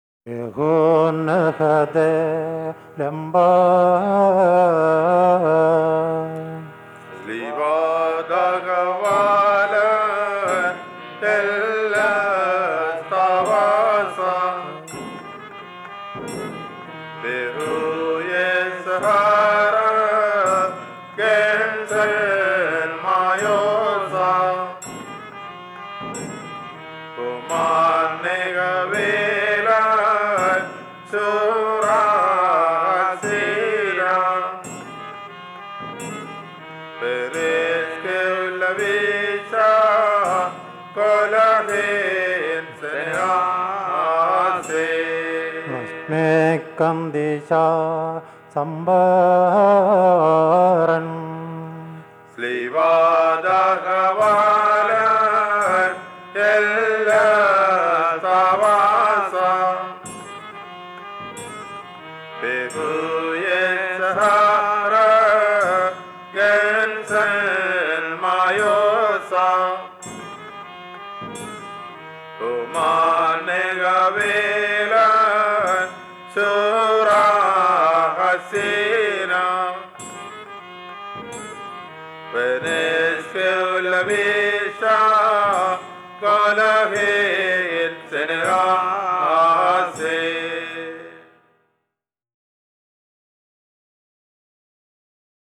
Harmonium
Triangle
Drum